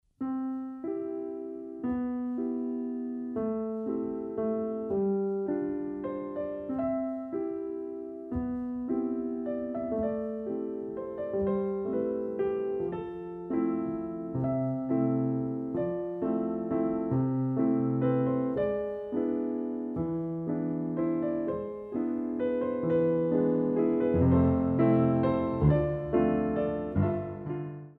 Compositions for Ballet Class
Rond de jambe à terre